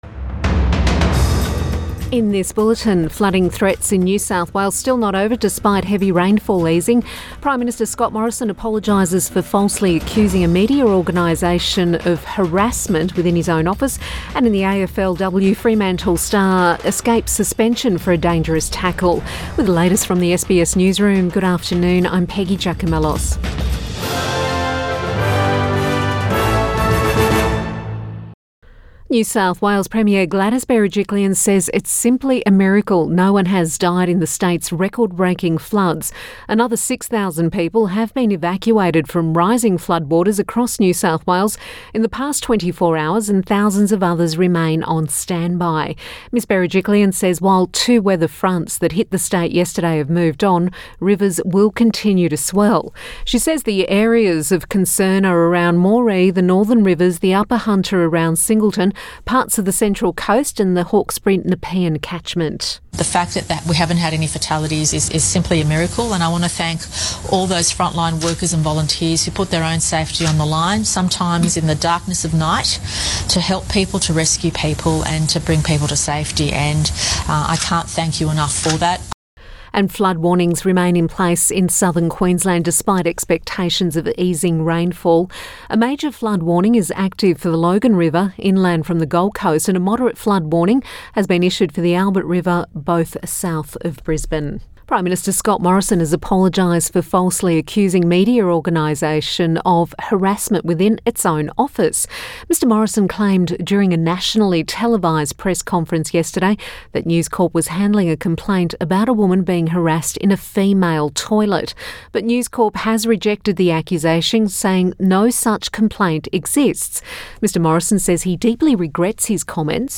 Midday bulletin 24 March 2021